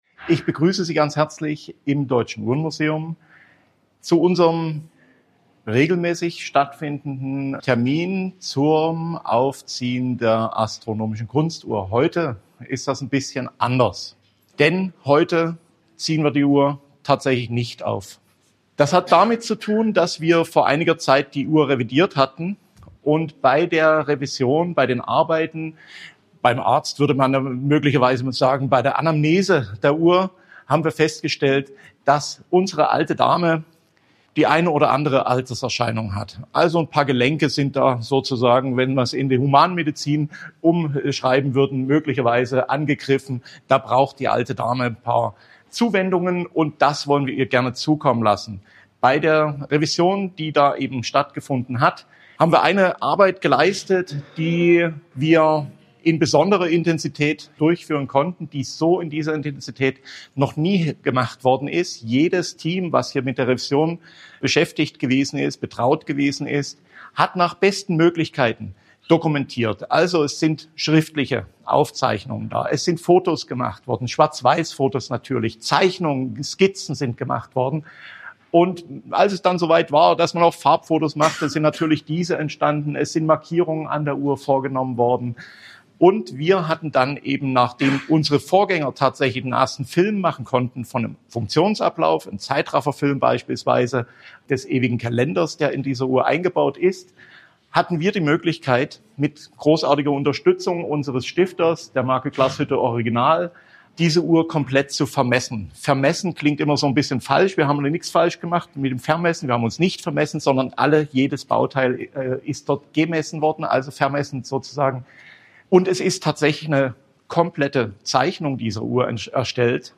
Am 05.12.2024 wurde die astronomische Kunstuhr von Hermann Goertz im Rahmen einer einzigartigen und öffentlichen Veranstaltung angehalten. Das Uhrwerk wurde einer umfassenden Revision unterzogen, bei der nun im abschließenden Schritt erstmals seit 100 Jahren Teile ausgetauscht werden – diese mussten speziell vermessen und gefertigt werden.